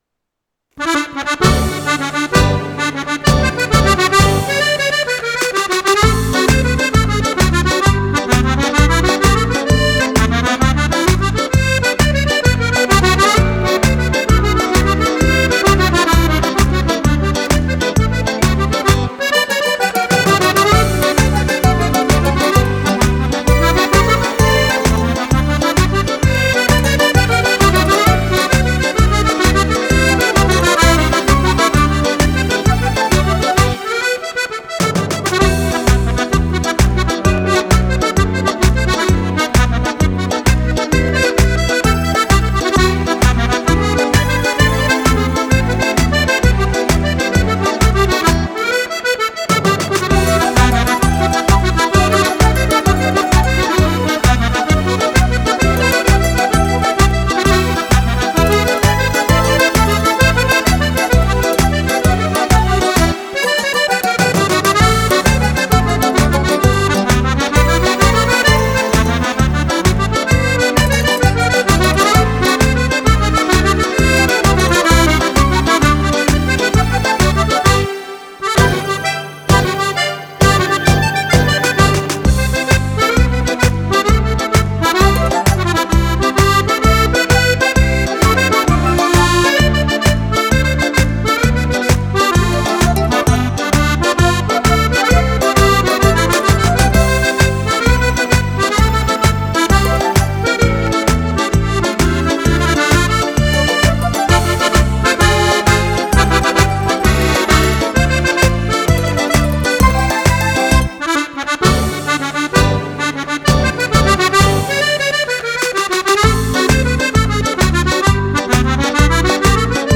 Tarantella